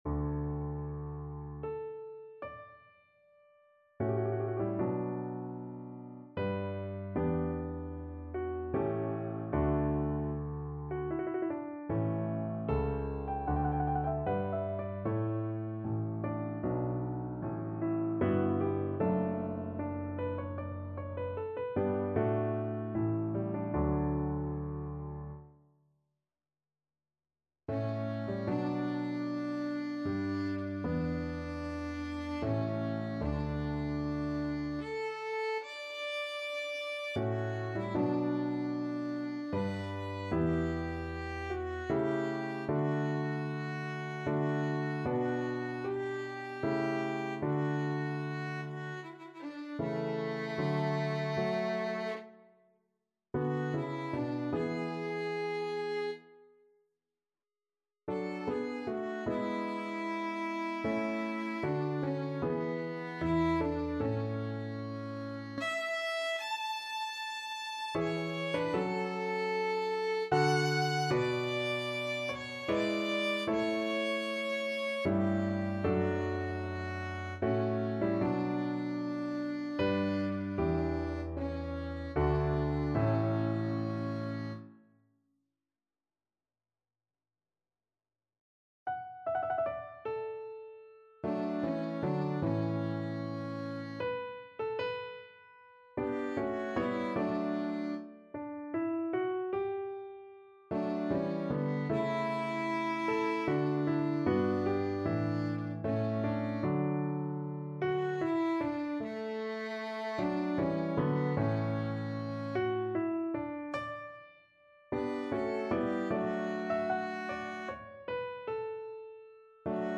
Larghetto (=76)
3/4 (View more 3/4 Music)